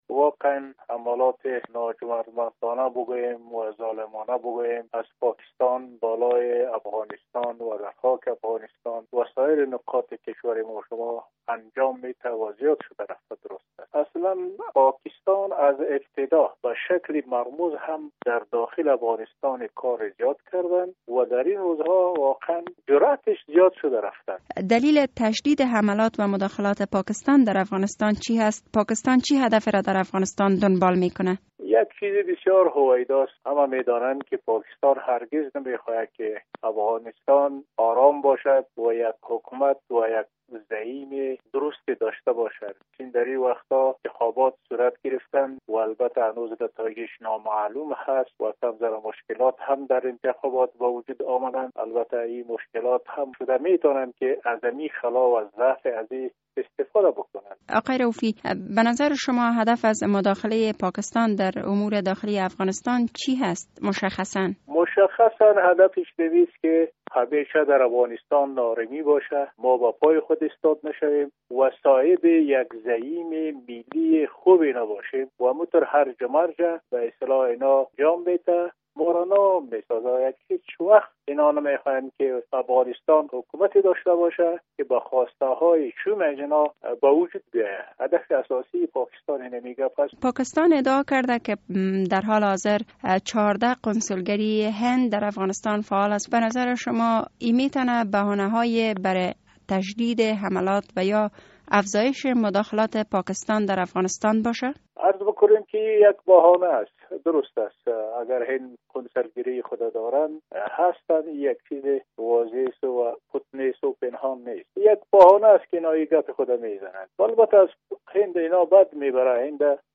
مصاحبه در مورد افزایش حملات و مداخلات پاکستان بر افغانستان